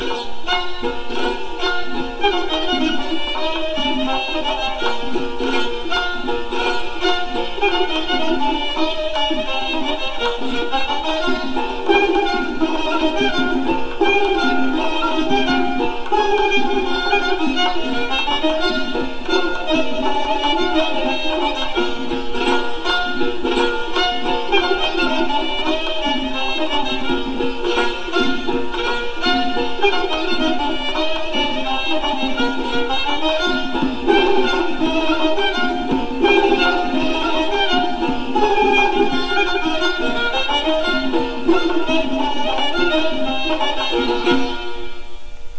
The Kamancheh Has four strings. It is tuned like the violin .
soundkamanchy.wav